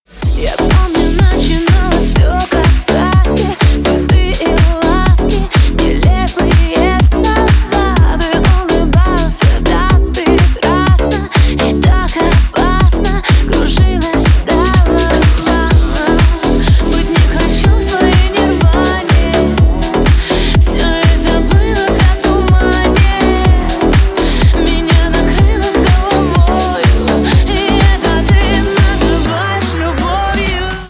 • Качество: 192, Stereo
громкие
deep house
Club House